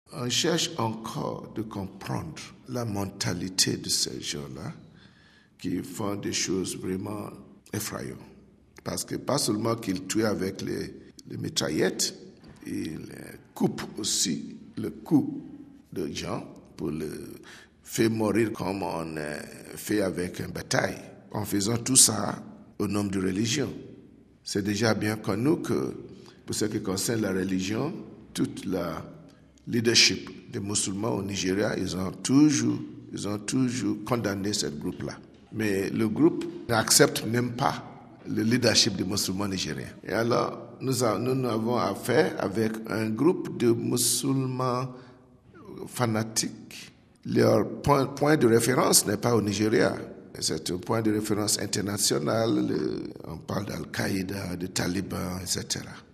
On écoute le cardinal John Onaiyekan : RealAudio